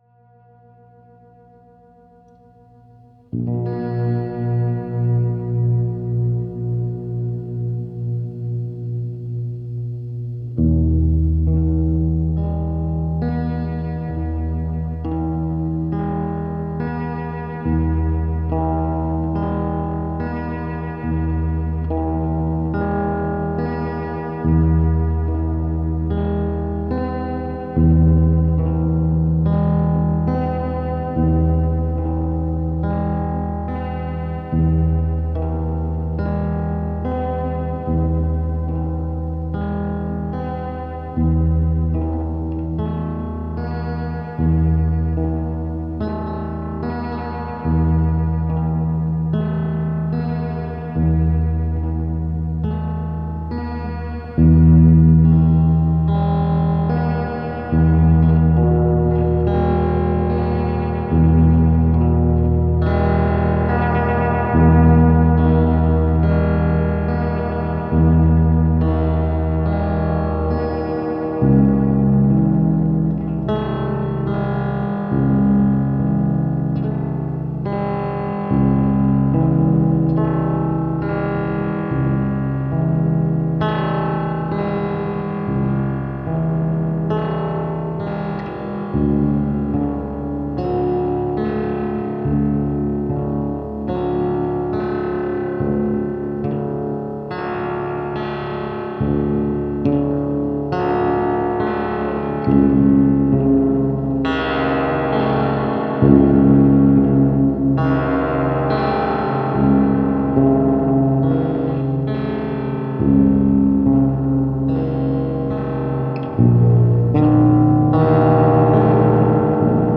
The carrier signal is modulated by a voltage controlled triangle (dagger) oscillator with a discrete OTA. A very wide frequency spectrum to be tuned in without any differing in waveshape. Temperature induced pitch fluctuation is a side effect of the discrete components which sweetly resembles the detuning of an instrument as the wood expands and contracts and the pegs loosen as it is plucked and strummed.
This circuit enhances the harmonic feild of electric signals as an analogue to a double coursed acoustic instrument. Centering the harmonic feild to a carfully tuned pitch creates bellish overtones and detuned phasing effects.